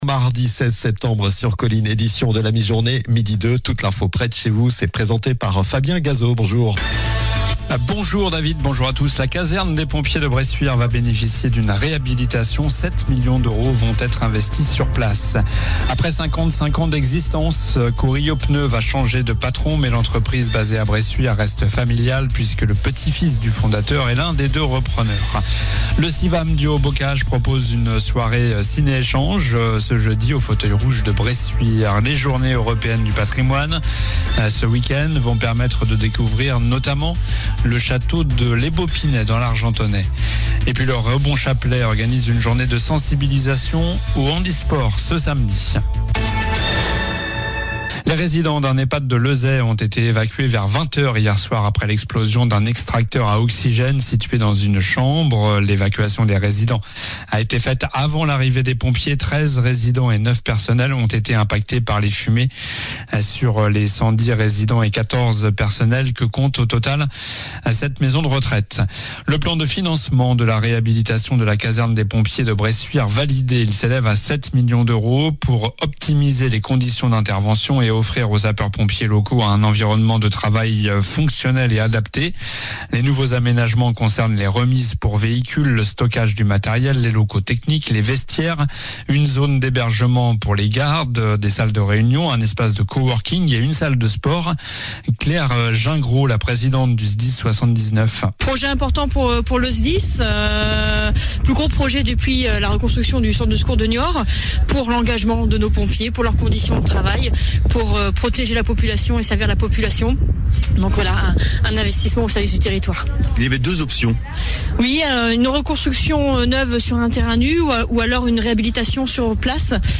COLLINES LA RADIO : Réécoutez les flash infos et les différentes chroniques de votre radio⬦
Journal du mardi 16 septembre (midi)